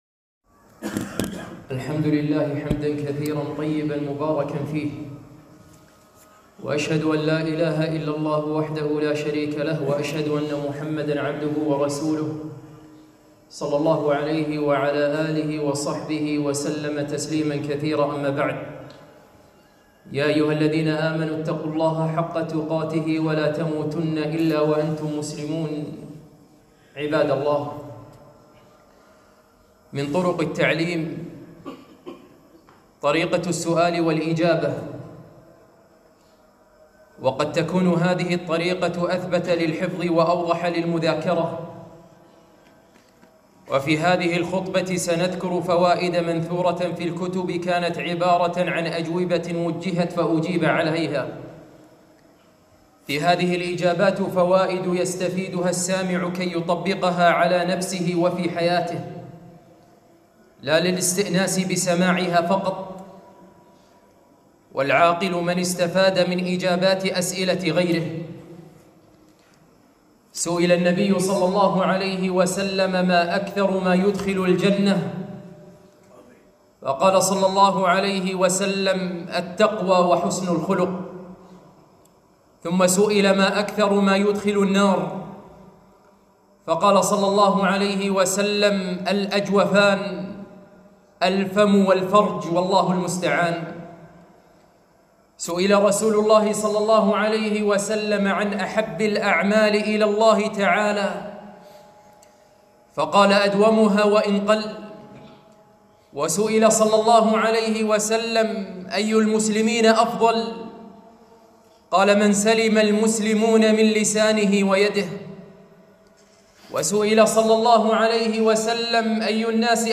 خطبة - إجابات لسؤالات